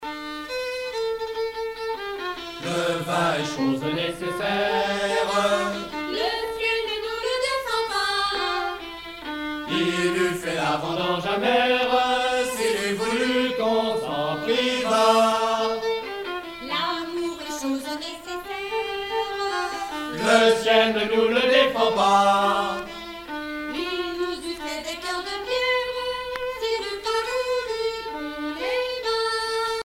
circonstance : bachique
Pièce musicale éditée